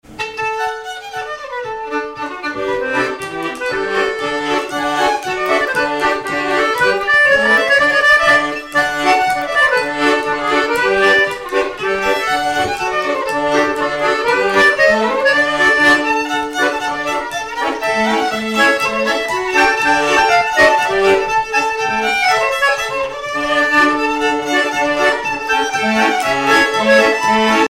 Miquelon-Langlade
danse : jig
violon
Pièce musicale inédite